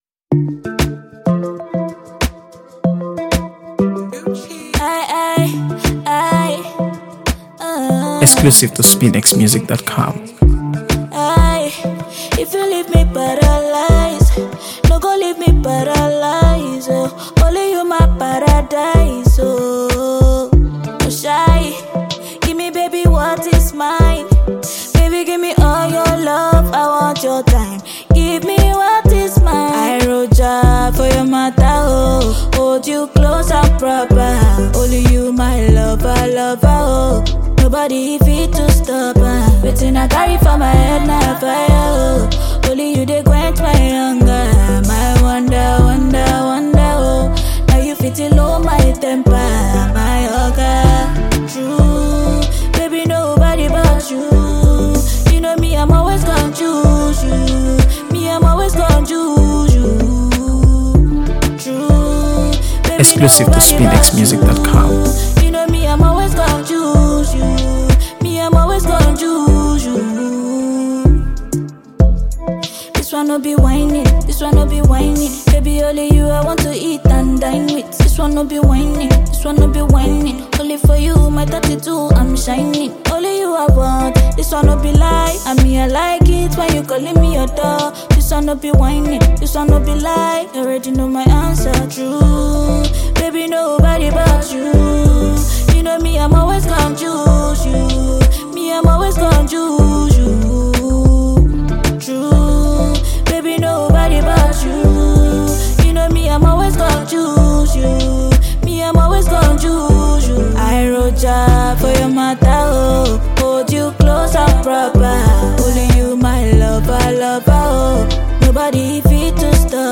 AfroBeats | AfroBeats songs
silky vocals, heartfelt lyrics, and masterful delivery
Produced with high-quality instrumentation and crisp mixing